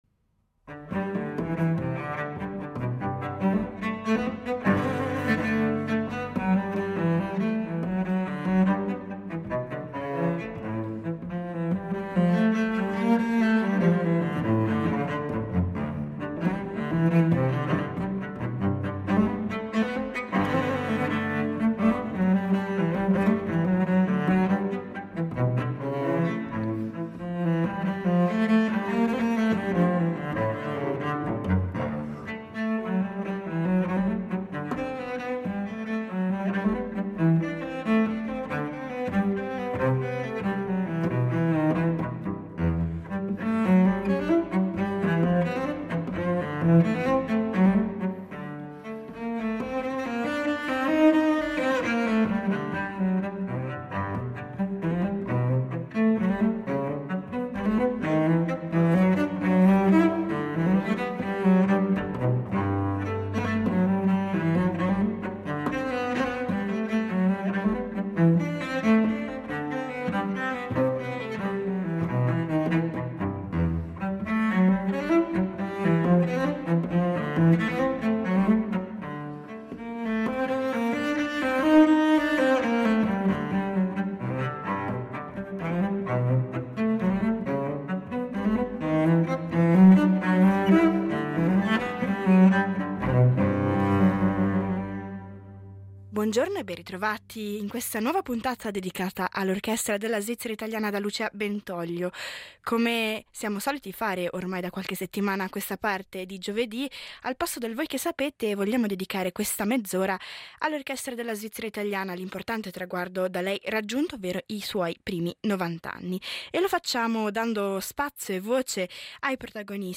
Una serie di interviste per raccontare l’Orchestra della Svizzera italiana